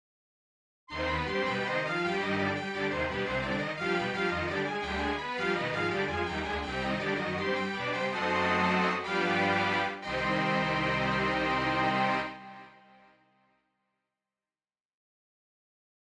A single patch demo of the Custom Orchestra 2 tape bank.
021-Custom-Orchestra-2.mp3